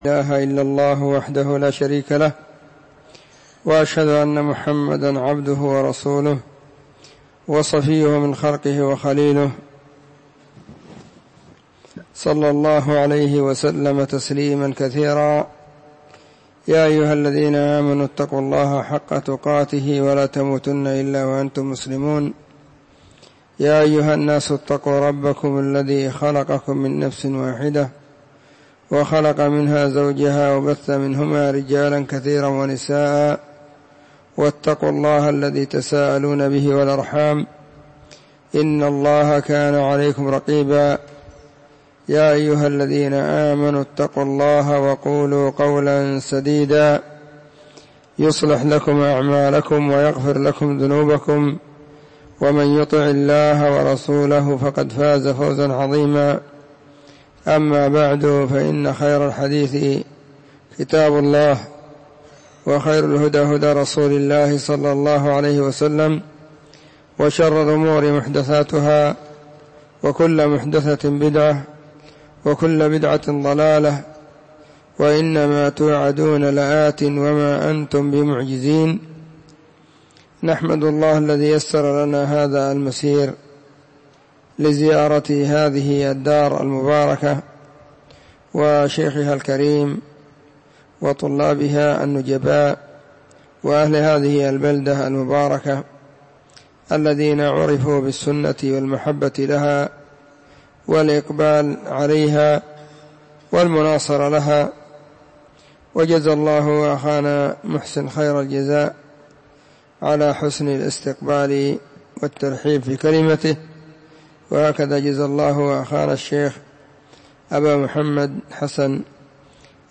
🎙 كلمة قيمة بعنوان: *💿الجنة ونعيمها💿*
📢 مسجد – الصحابة – بالغيضة – المهرة، اليمن حرسها الله.